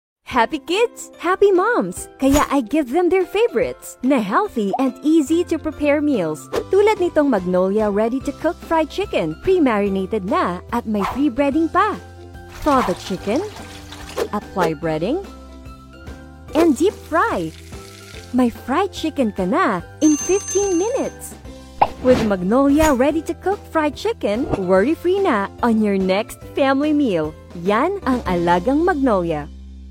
Female
My voice type is deep, authoritative, adult, teenager, and corporate.
Social Media Ads